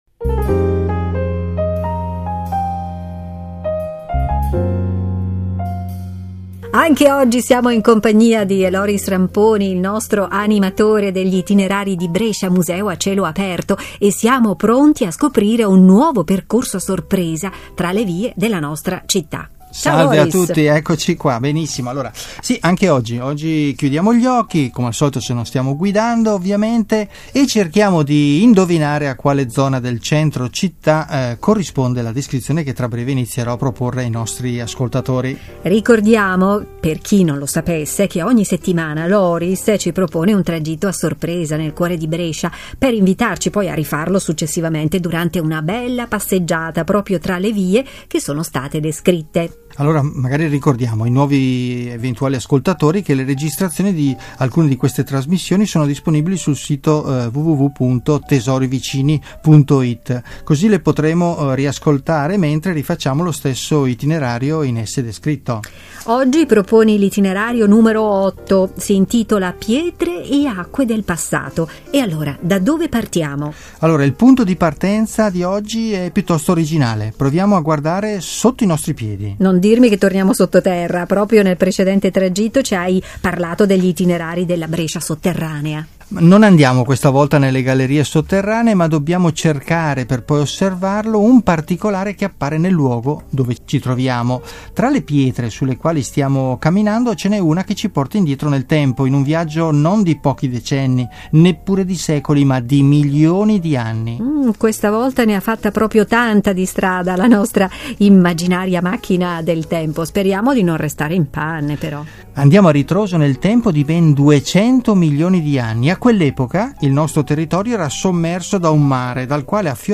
audio-guida e itinerari per passeggiare tra le vie di Brescia